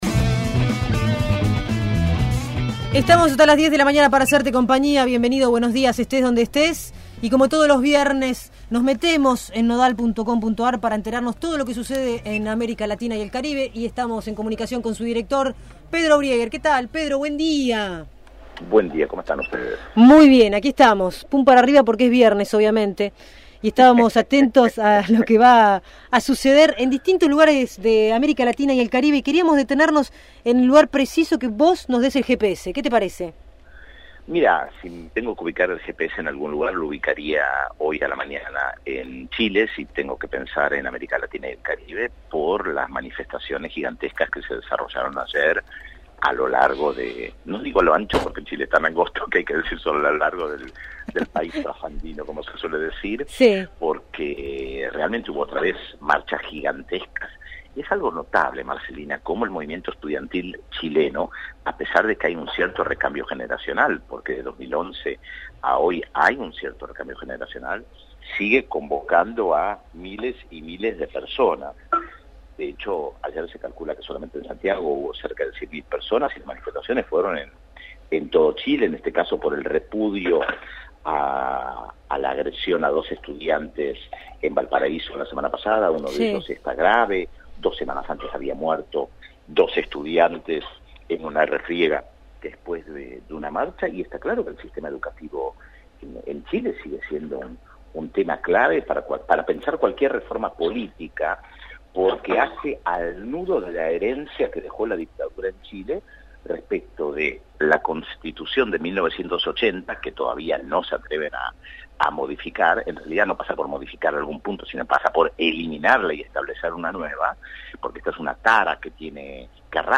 El periodista Pedro Brieger realizó su columna de política internacional en el programa Radiópolis Ciudad Invadida.